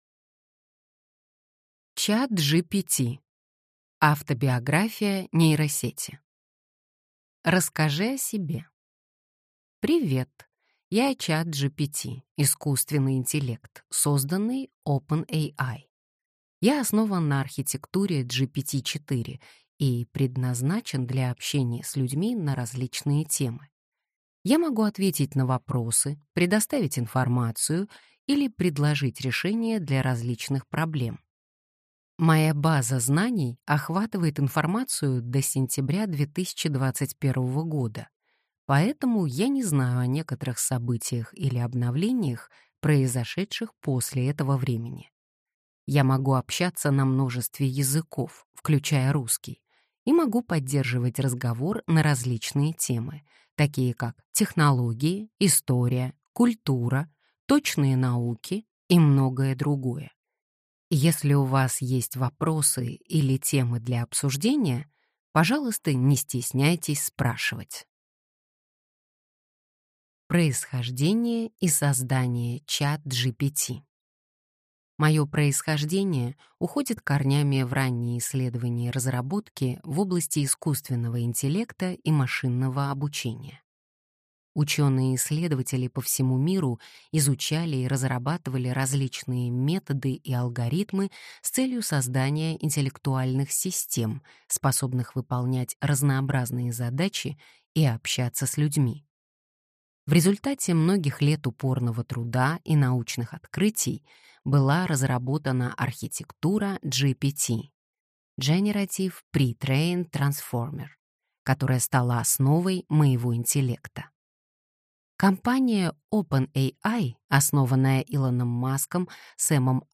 Аудиокнига Автобиография нейросети | Библиотека аудиокниг